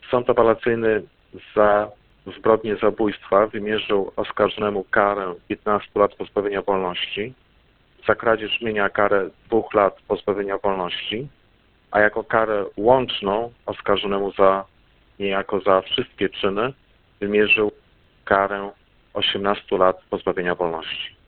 O wysokości wyroku informuje rzecznik prasowy